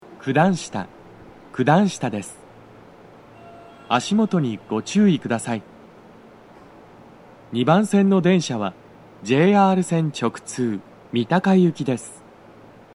スピーカー種類 BOSE天井型
足元注意喚起放送が付帯されており、粘りが必要です。
男声
到着放送2